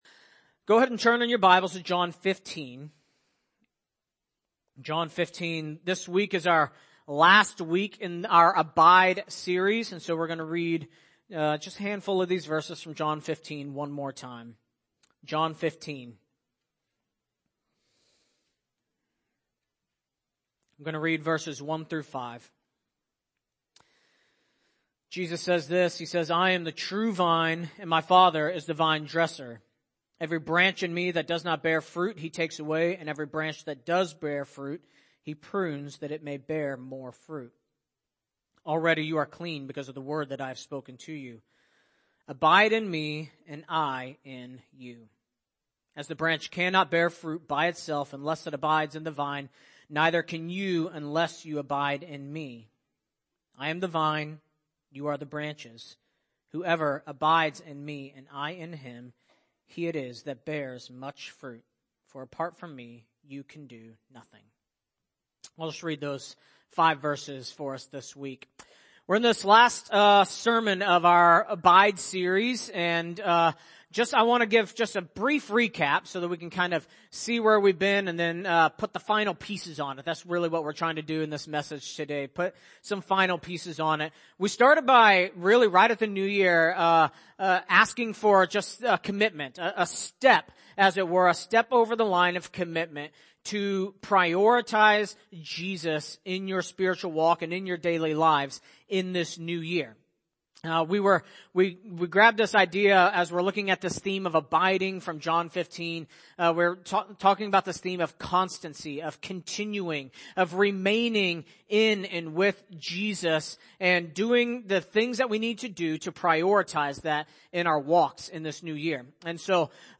January 29, 2023 (Sunday Morning)